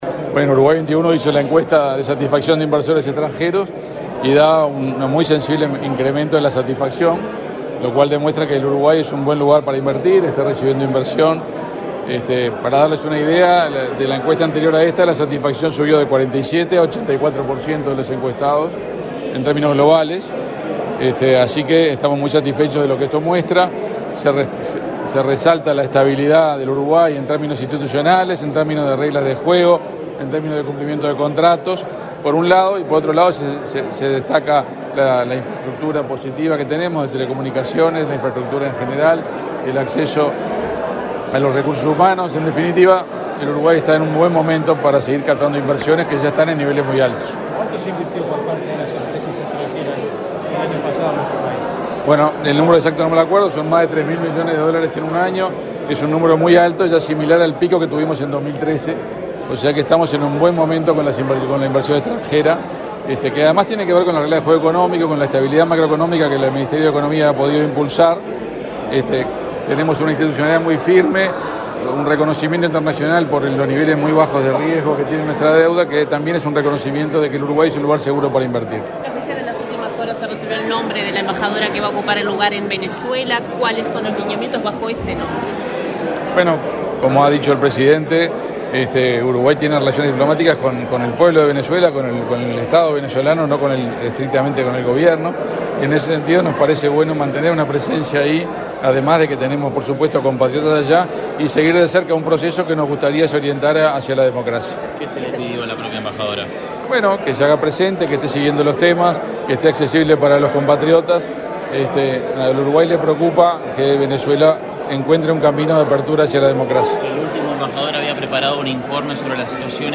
Declaraciones del canciller Omar Paganini a la prensa
Declaraciones del canciller Omar Paganini a la prensa 13/03/2024 Compartir Facebook X Copiar enlace WhatsApp LinkedIn El canciller Omar Paganini dialogó con la prensa, luego de participar, este miércoles 13 en la Torre Ejecutiva, en la presentación de resultados de la encuesta a inversores extranjeros, realizada por el instituto Uruguay XXI.